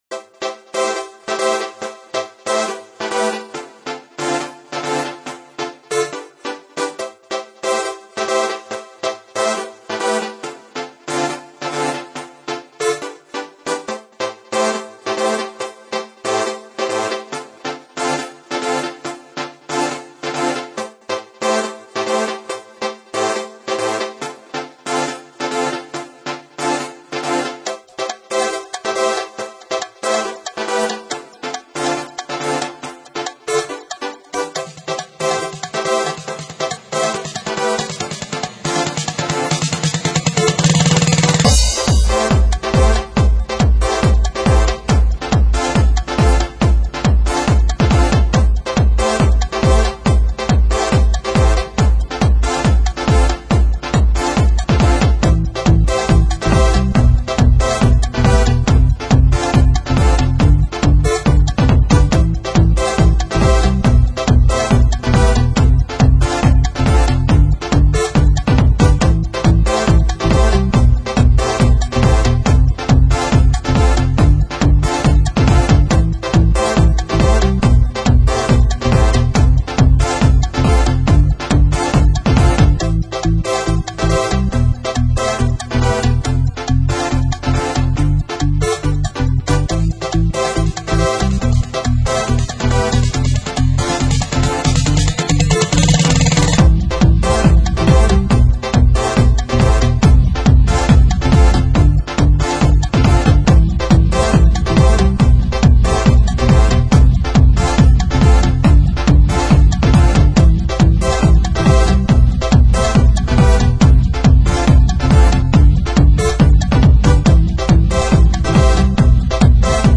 This track was created entirely with Dance-e-Jay. No other samples were used. I think it could do with an Uplifting vocal to finish it completly but, it sounds fine as an instrumental.